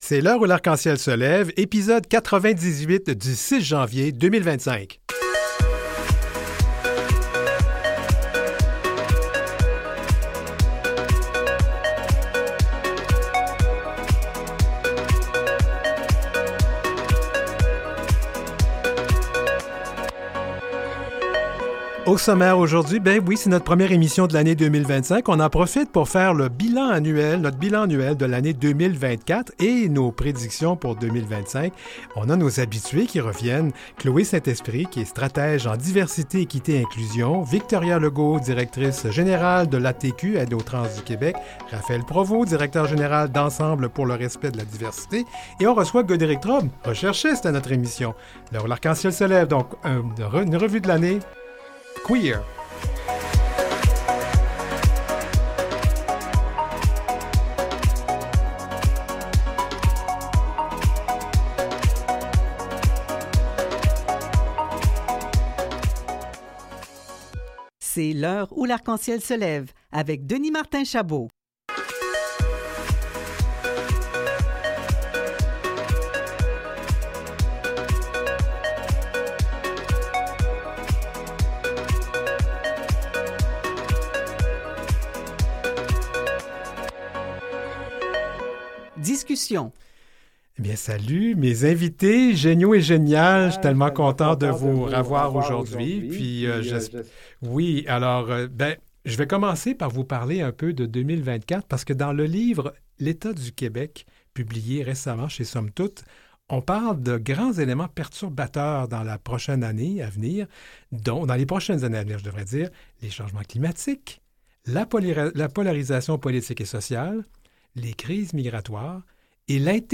Jean-Yves Duclos, ministre des Services publics et Approvisionnements du Canada, réagit à la démisison de Justin Trudeau